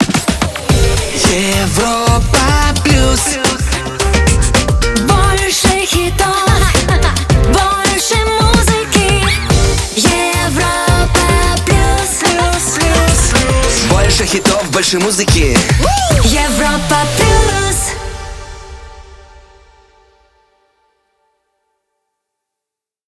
Jingle